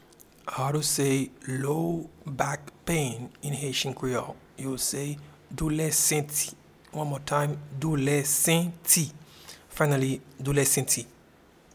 Pronunciation and Transcript:
Low-back-pain-in-Haitian-Creole-Doule-senti.mp3